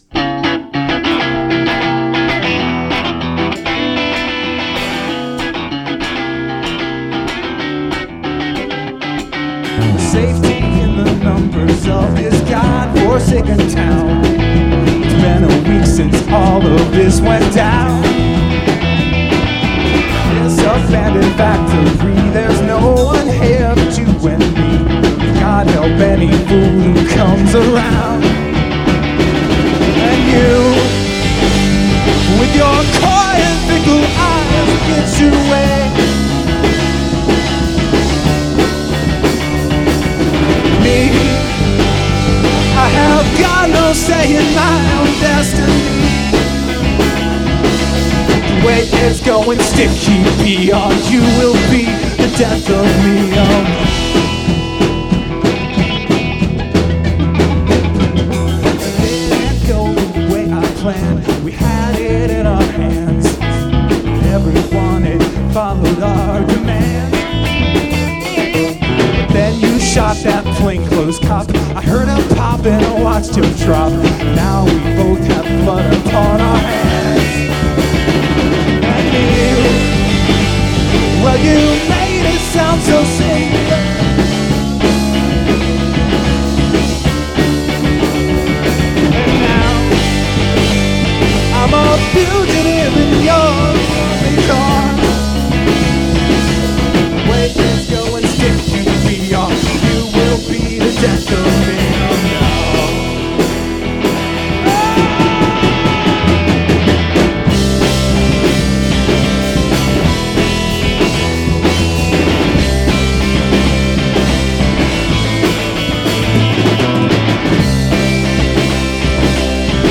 Here are some random MP3s from my live shows: